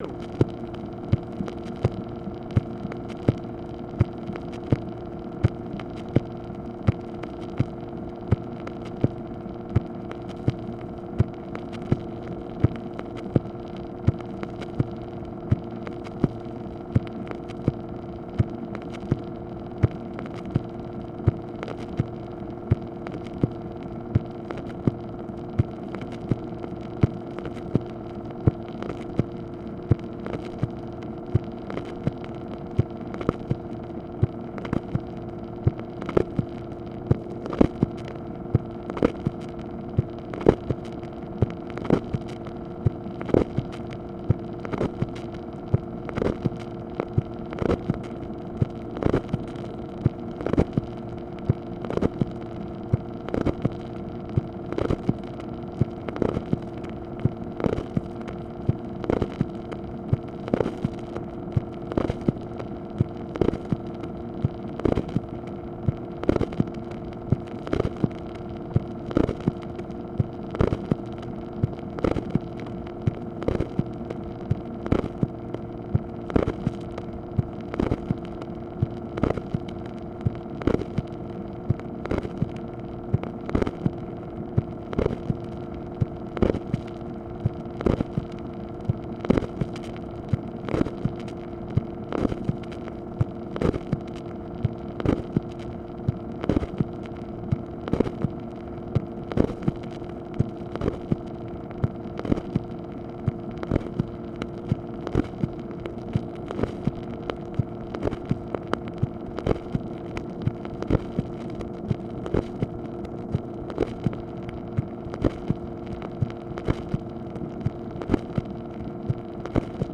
MACHINE NOISE, February 6, 1965
Secret White House Tapes | Lyndon B. Johnson Presidency